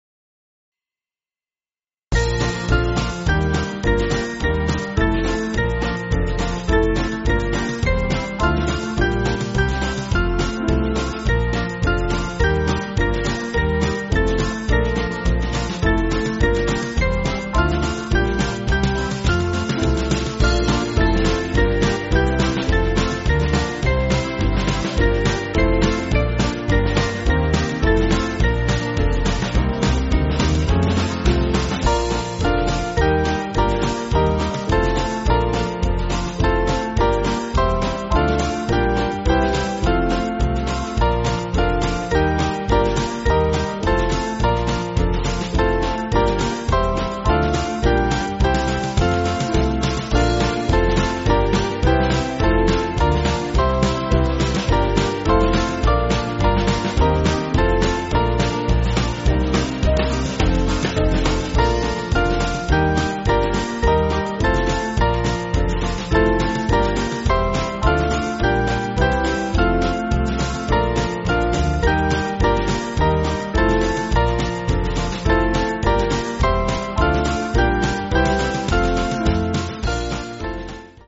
Small Band
(CM)   3/Bb